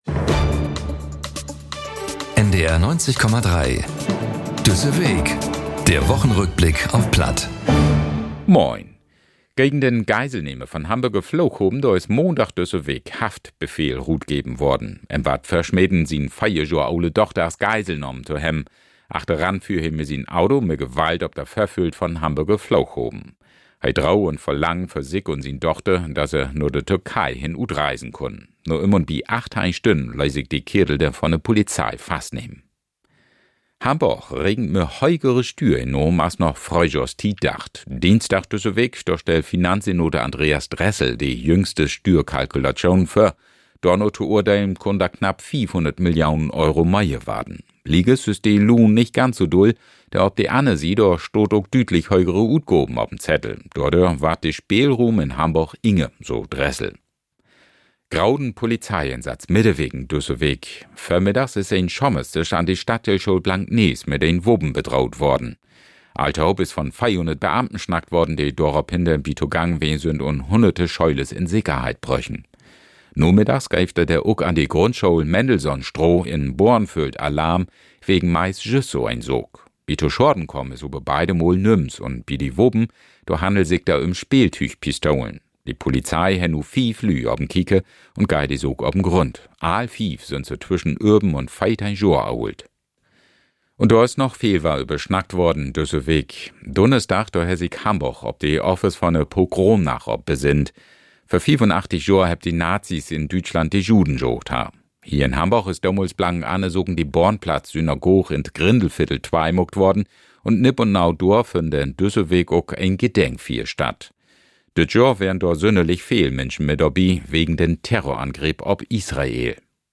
Nachrichten - 19.02.2024